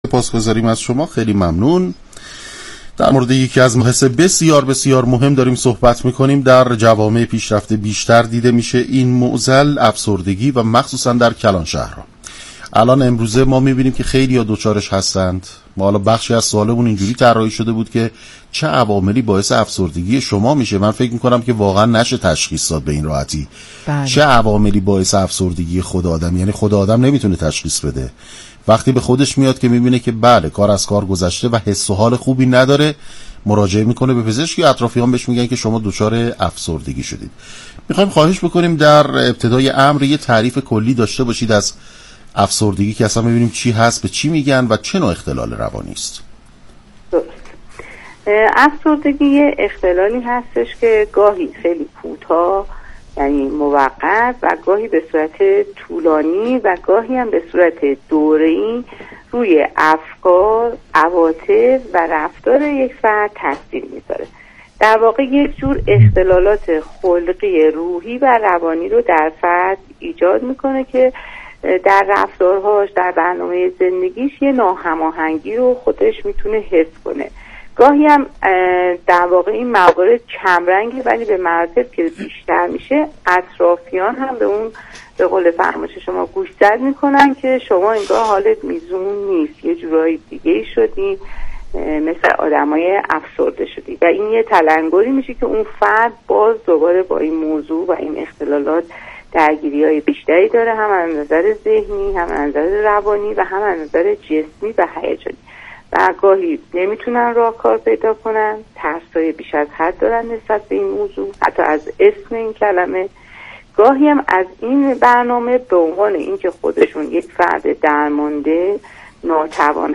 /گفتگوی رادیویی/